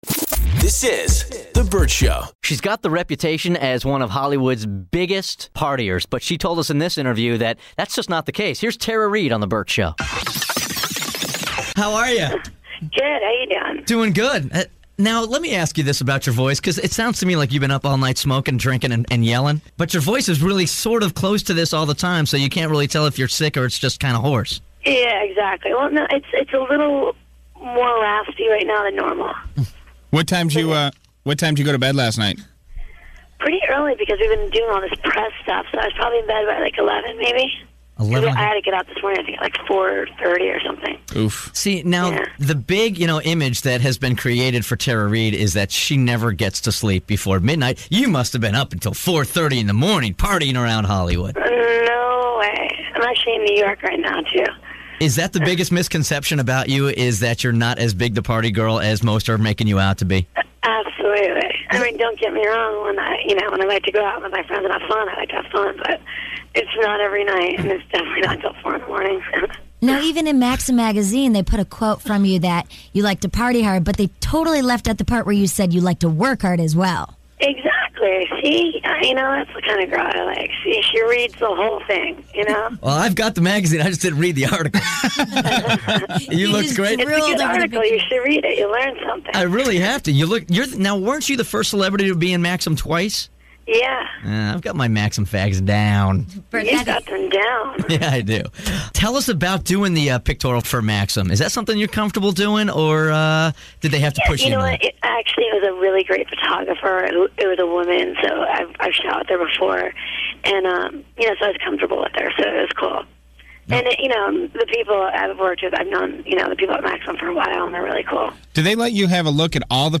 Vault: Interview With Tara Reid